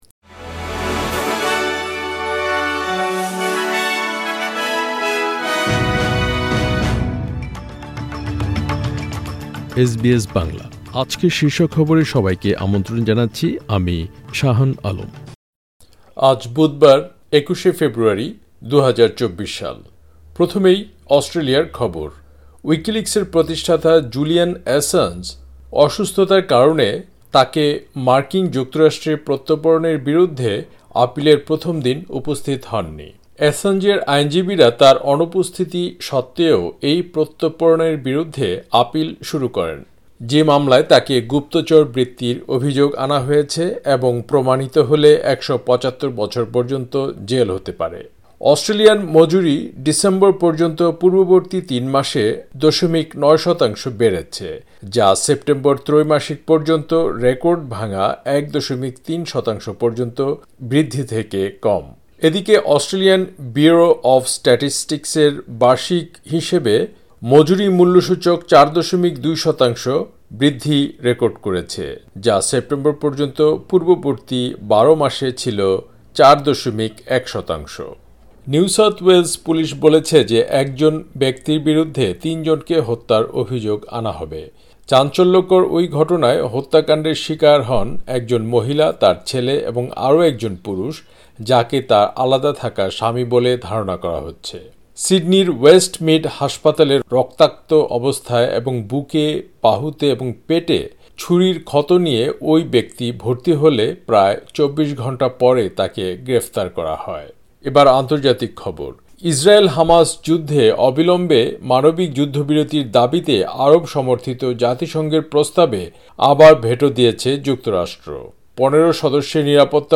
এসবিএস বাংলা শীর্ষ খবর: ২১ ফেব্রুয়ারি, ২০২৪